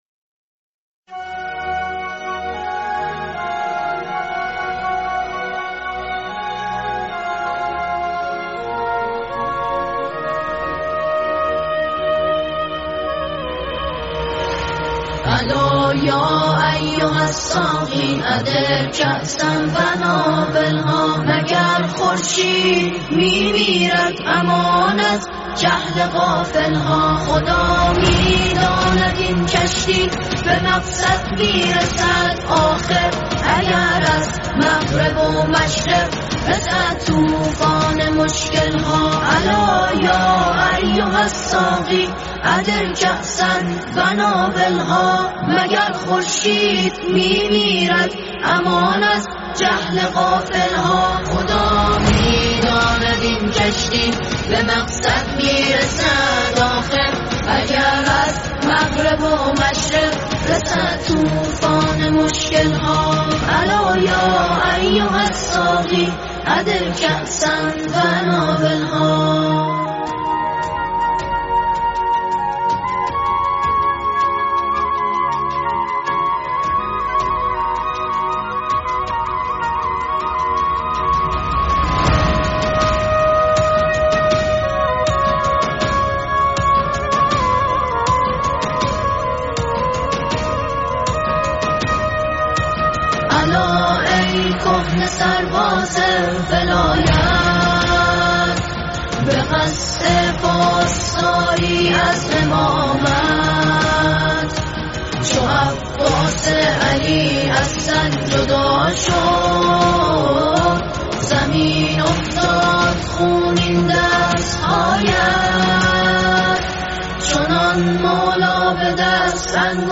شیوه اجرا: اركستر